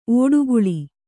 ♪ ōḍuguḷi